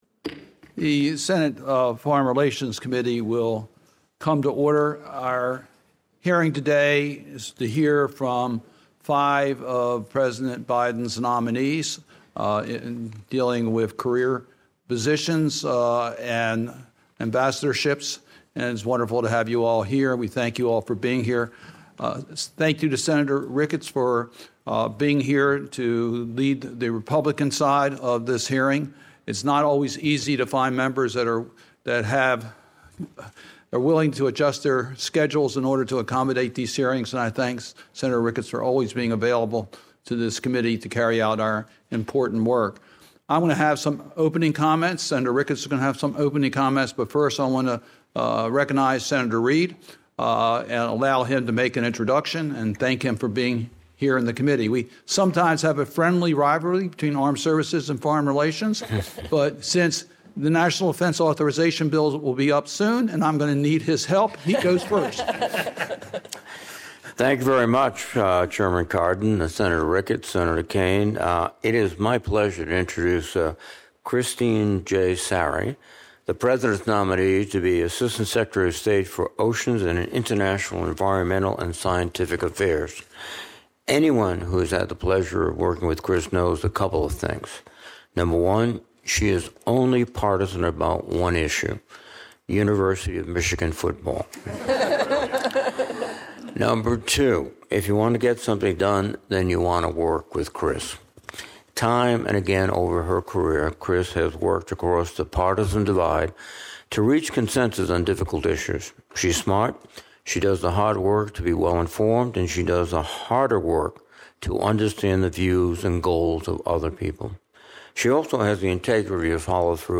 Nomination Hearing on May 9, 2024 at 6:00 AM